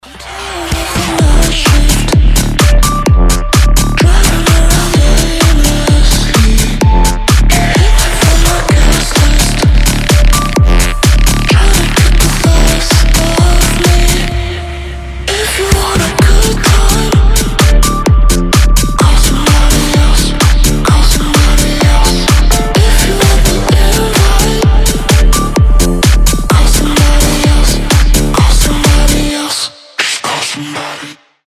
• Качество: 320, Stereo
басы
стильные
electro house